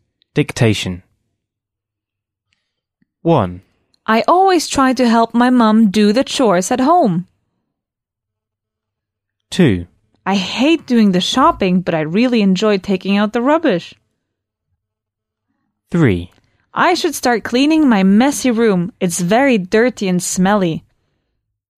Dictation.